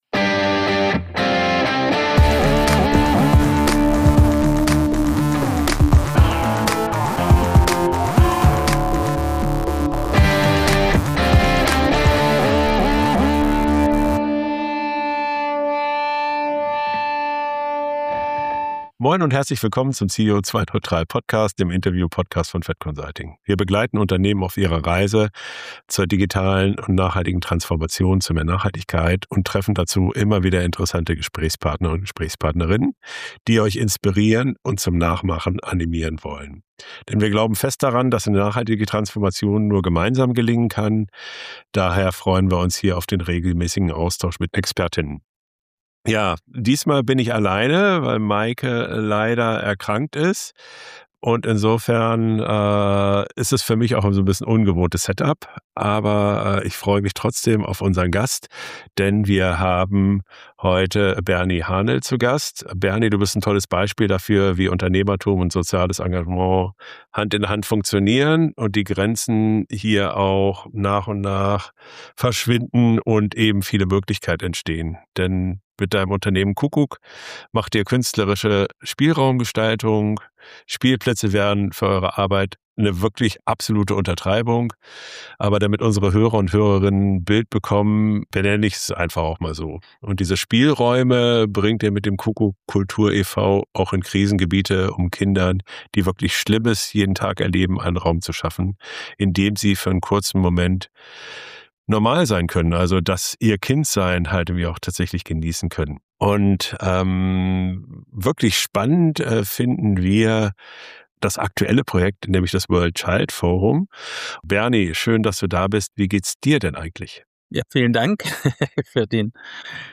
CEO2-neutral - Der Interview-Podcast für mehr Nachhaltigkeit im Unternehmen